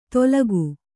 ♪ tolagu